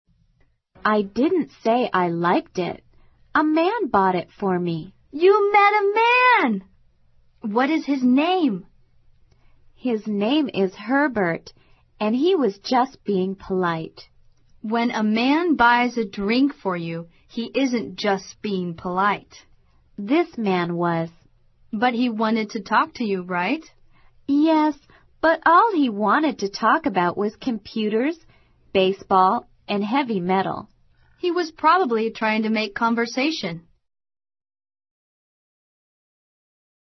网络社交口语对话第26集：他大概是想找话题